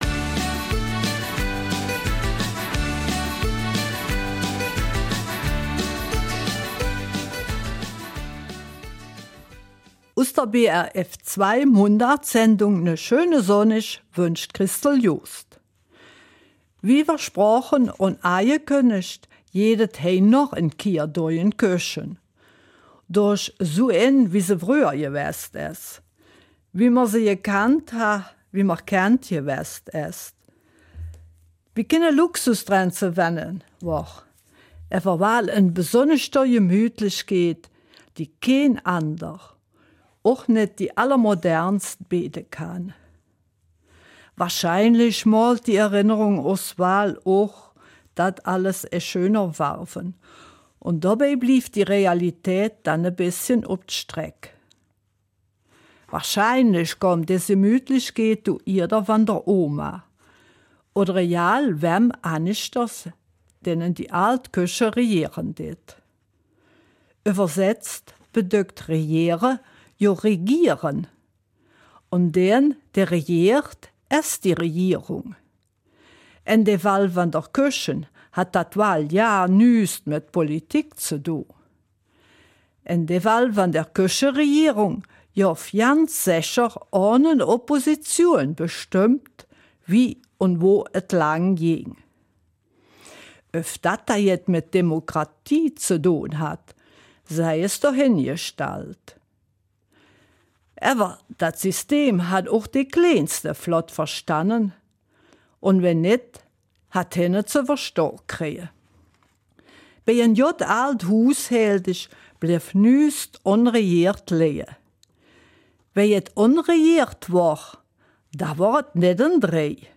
Eifeler Mundart: Küchenutensilien